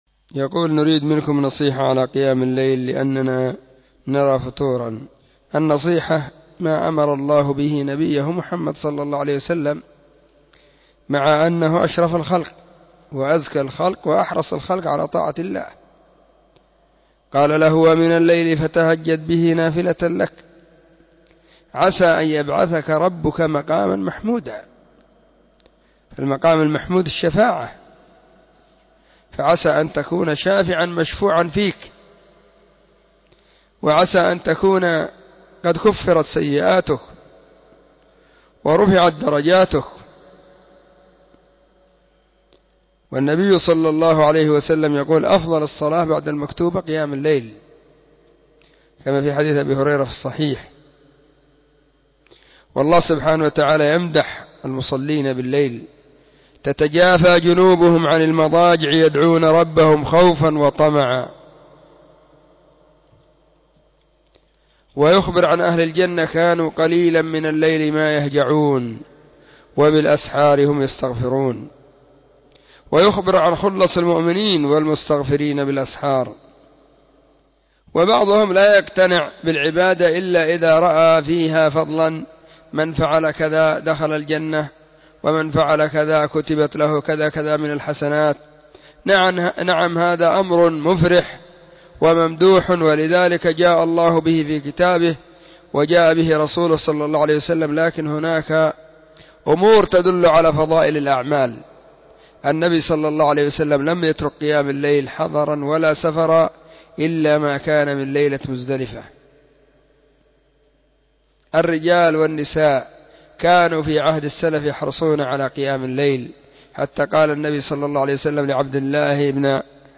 📢مسجد – الصحابة – بالغيضة – المهرة، اليمن حرسها الله.